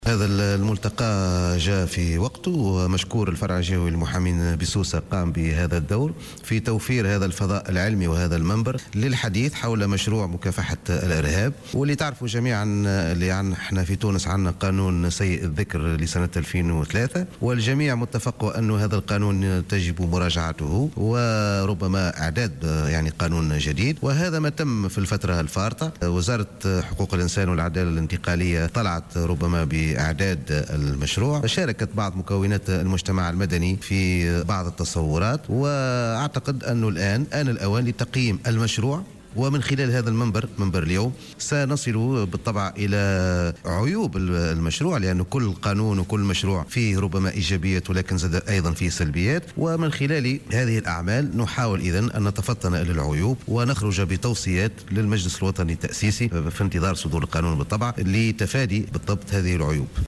Le bâtonnier des avocats Mohamed Fadhel Mahfoudh a déclaré au micro de Jawhara Fm, en marge du premier forum scientifique sur le nouveau projet de loi pour lutter contre le terrorisme tenu à Sousse, que ce forum a été consacrée pour parler du projet de la lutte contre le terrorisme, surtout avec les critiques dont la loi de 2003 a fait l'objet.\